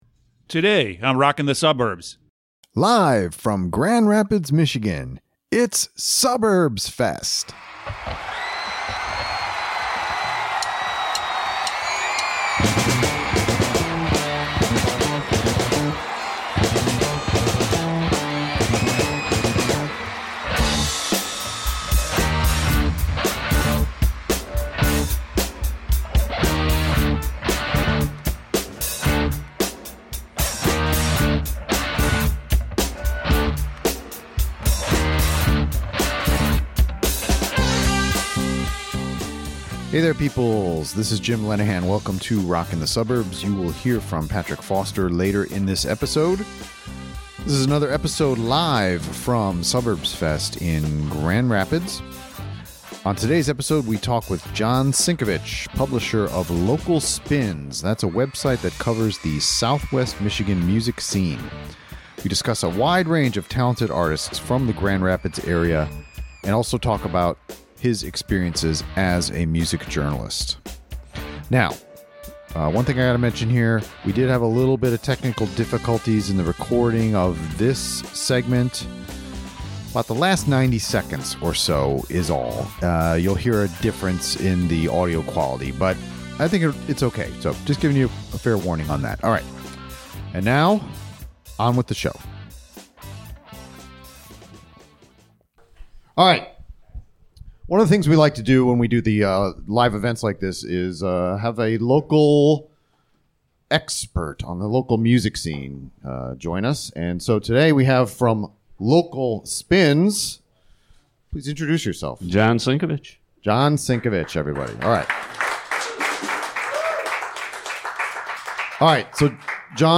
We are coming at you live from Suburbs Fest in Grand Rapids MI.